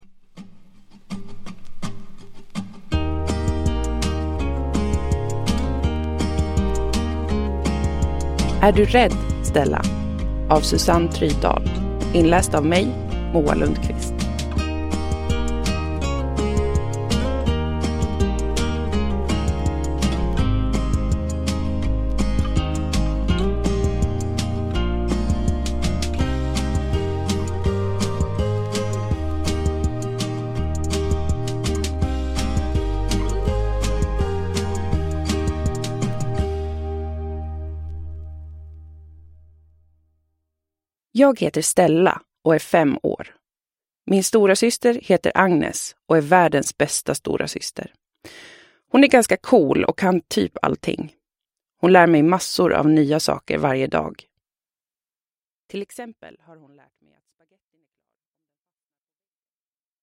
Uppläsare